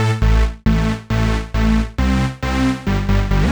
Index of /musicradar/future-rave-samples/136bpm